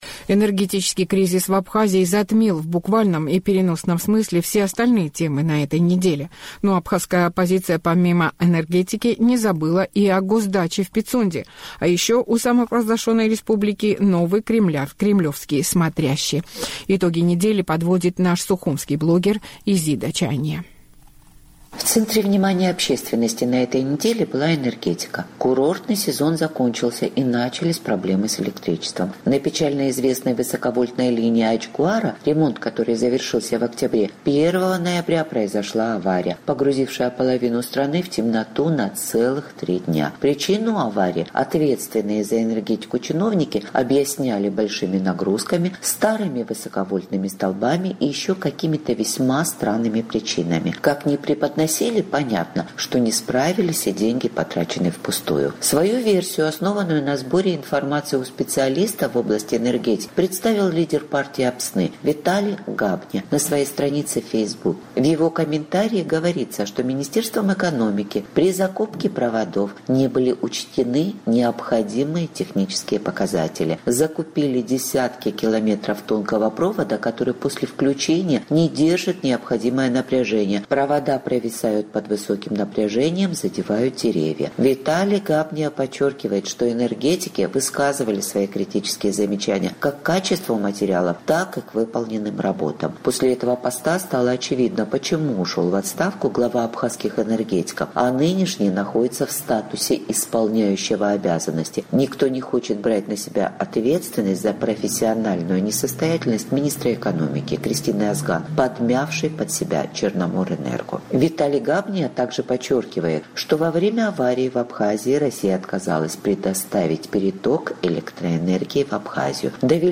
Итоги недели для Абхазии подводит сухумский блогер